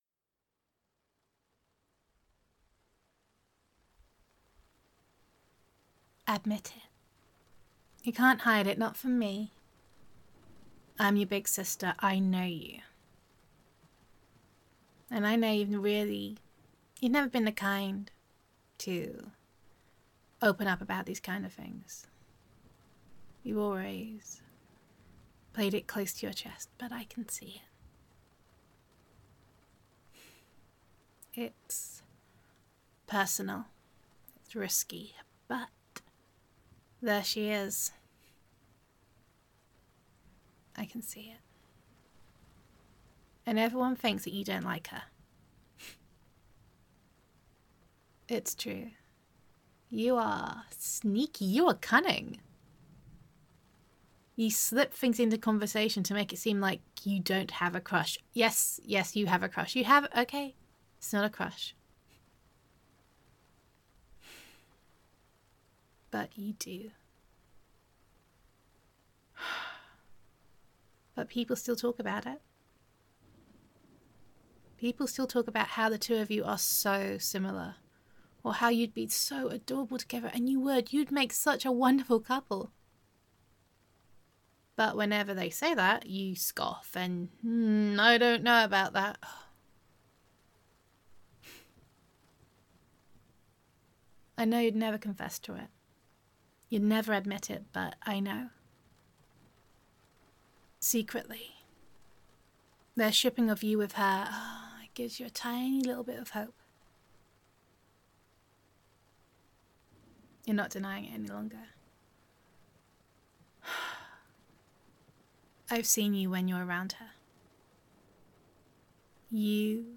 [F4A] Big Sister Knows All [Loving]
[Big Sister Roleplay]